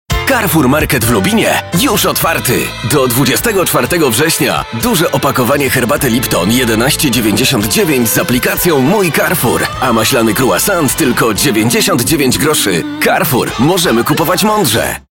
Male 20-30 lat
Young, vital and dynamic voice, known from VIVA Polska and NICKELODEON.
Spot reklamowy